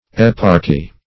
Eparchy \Ep"arch*y\, n. [Gr.